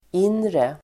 Uttal: ['in:re]